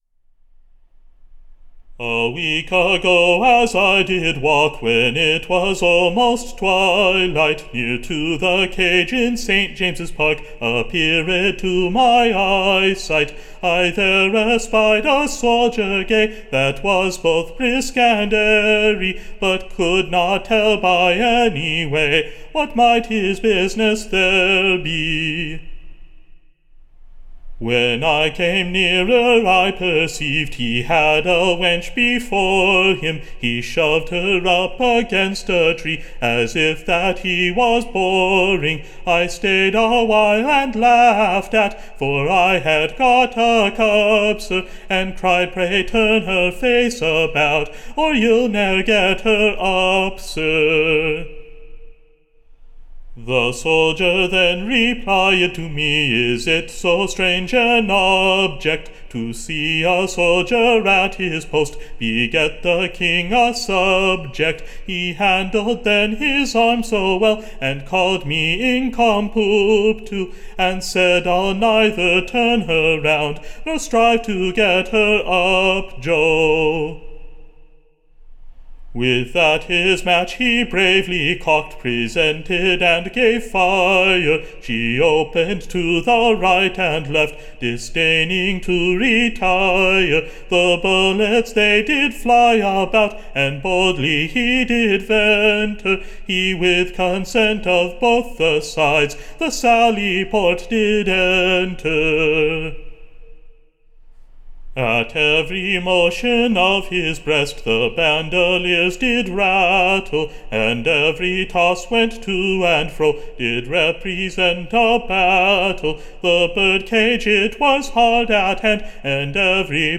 Recording Information Ballad Title You'l never get her up, / Or, Love in a Tree. / Being a pleasant new Song, shewing how a Maid was got with Child, without lying / with a Man.